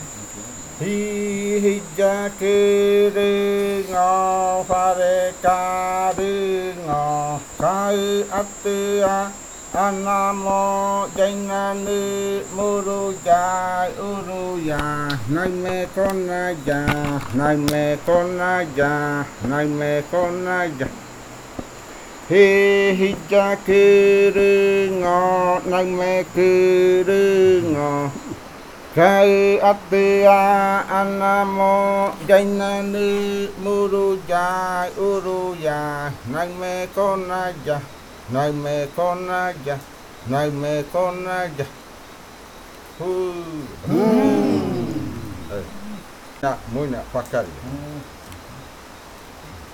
Leticia, Amazonas, (Colombia)
Grupo de danza Kaɨ Komuiya Uai
Canto fakariya de la variante Muinakɨ (cantos de la parte de abajo).
Fakariya chant of the Muinakɨ variant (Downriver chants).